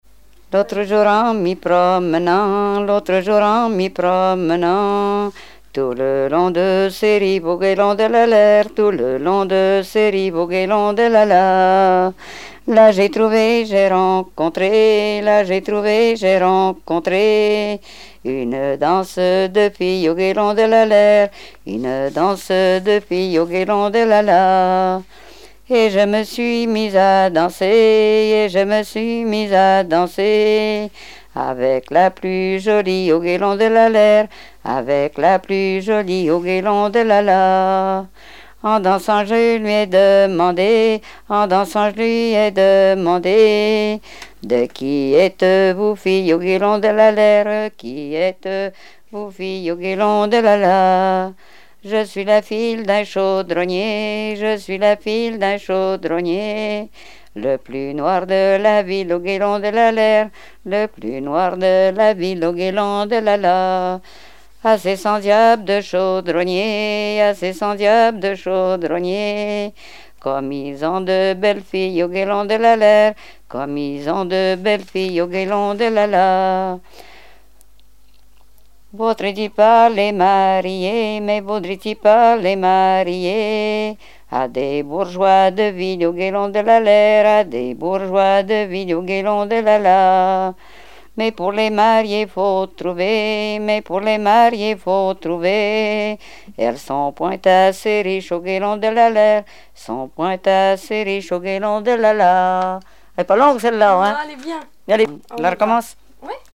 danse : ronde : grand'danse
Répertoire de chansons traditionnelles et populaires
Pièce musicale inédite